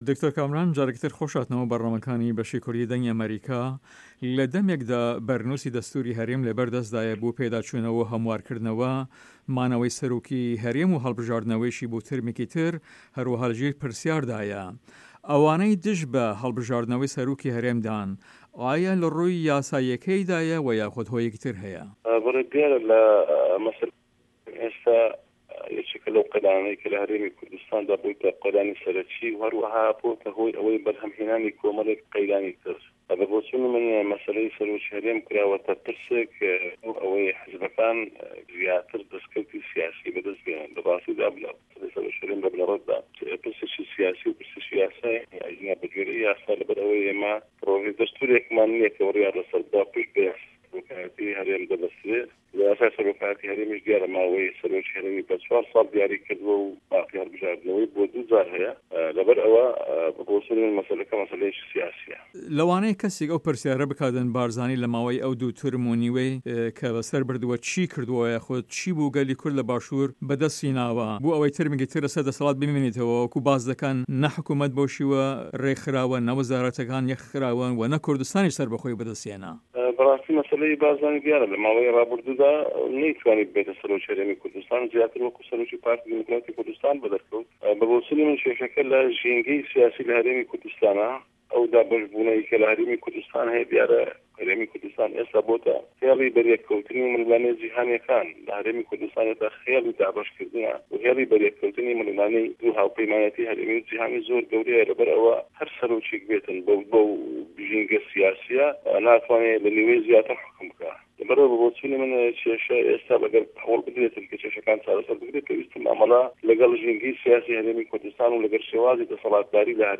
هه‌ڤپه‌یڤینێکدا